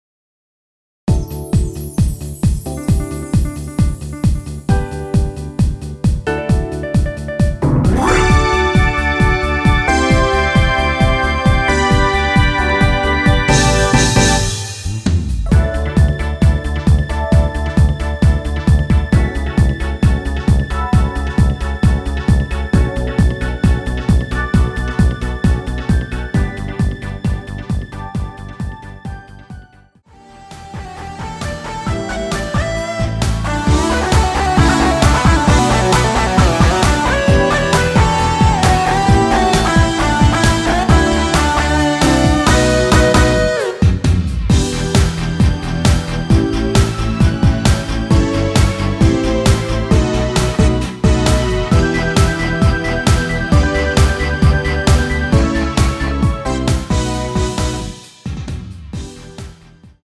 MR 입니다.
앞부분30초, 뒷부분30초씩 편집해서 올려 드리고 있습니다.
중간에 음이 끈어지고 다시 나오는 이유는